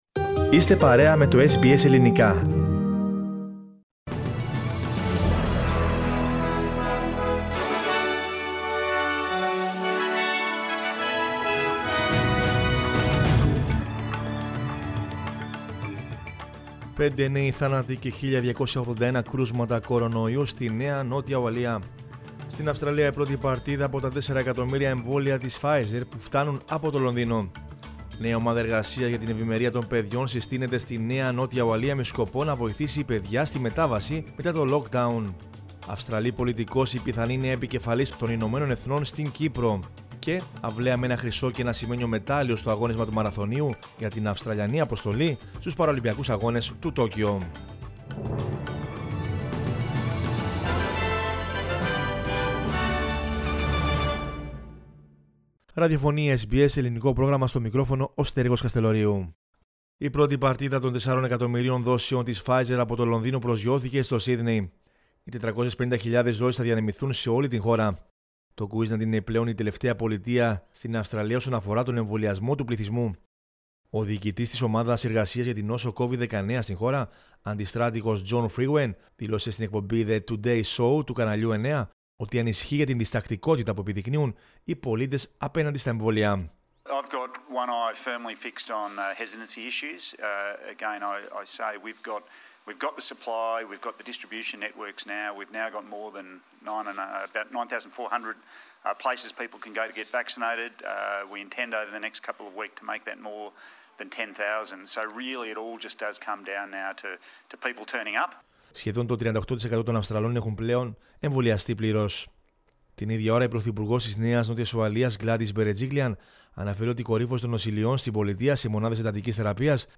News in Greek from Australia, Greece, Cyprus and the world is the news bulletin of Monday 6 September 2021.